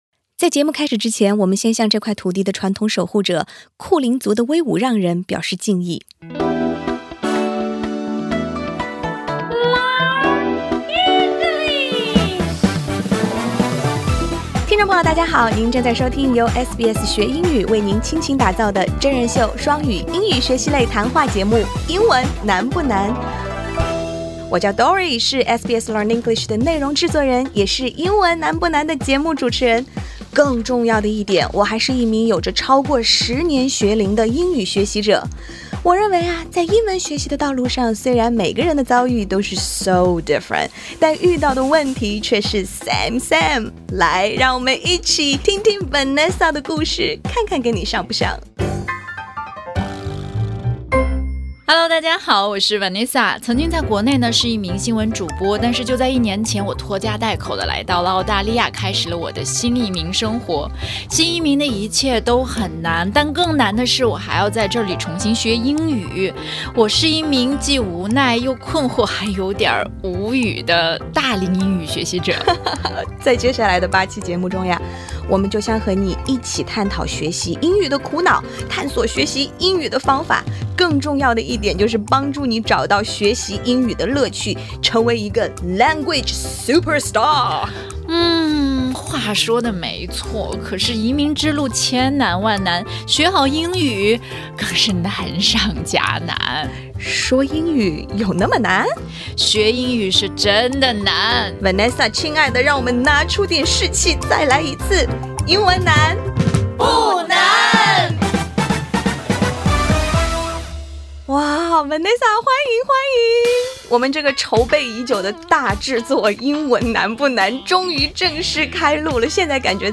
Sound design and music composition